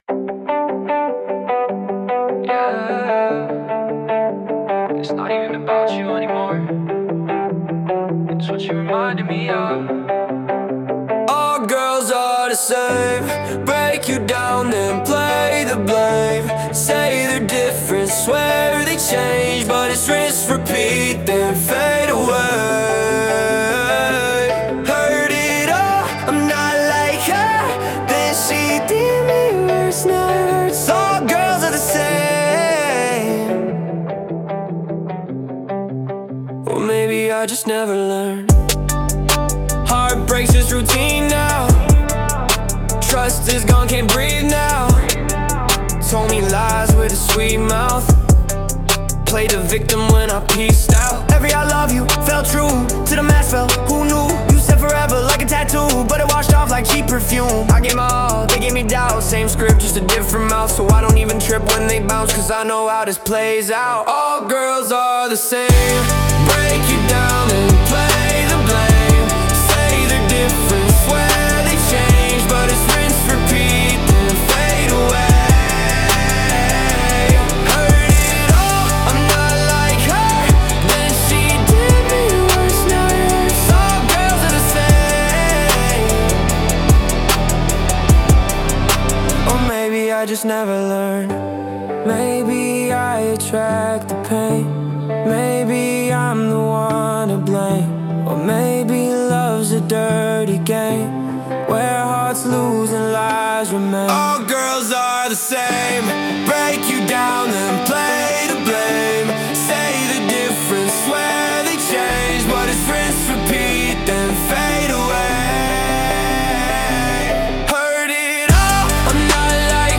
Royalty-Free Pop Rap music track
Genre: Pop Rap Mood: sad boy